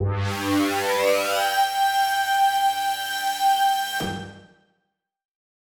Index of /musicradar/future-rave-samples/Poly Chord Hits/Straight
FR_ProfMash[hit]-G.wav